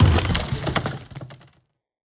car-wood-impact.ogg